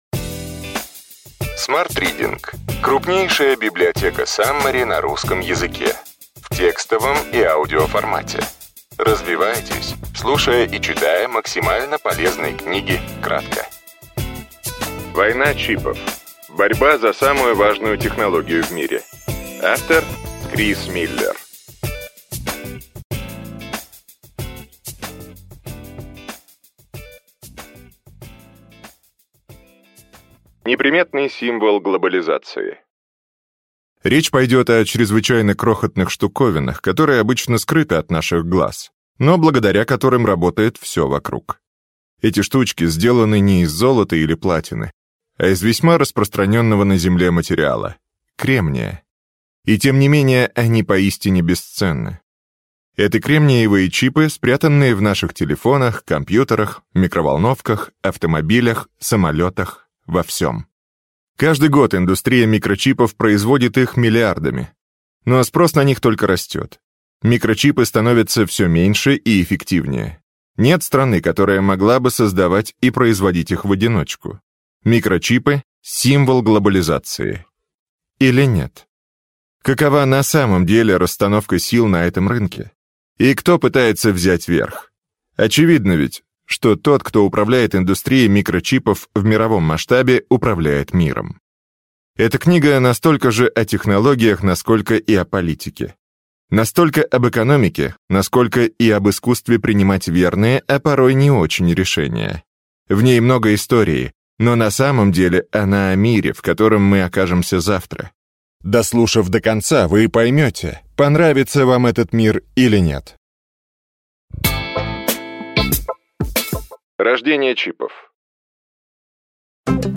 Аудиокнига Война чипов. Борьба за самую важную технологию в мире. Крис Миллер. Саммари | Библиотека аудиокниг